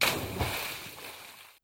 WaterCollision.wav